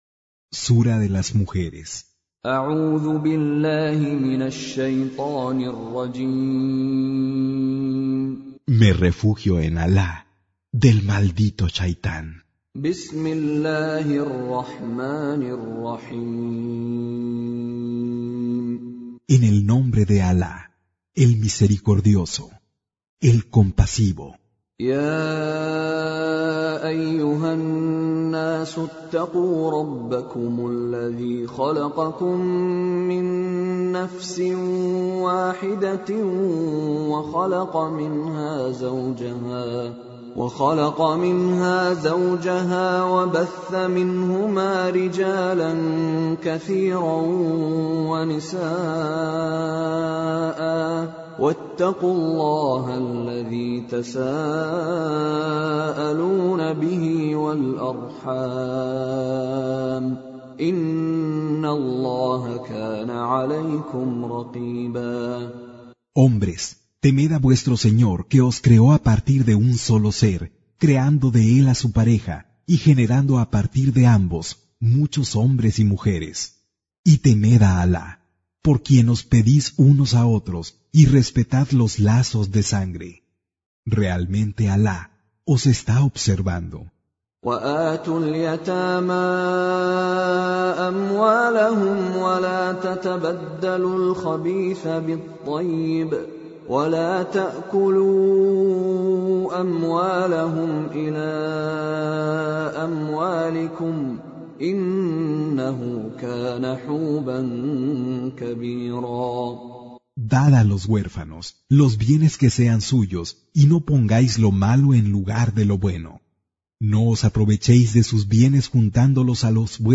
Con Reciter Mishary Alafasi
Surah Repeating تكرار السورة Download Surah حمّل السورة Reciting Mutarjamah Translation Audio for 4.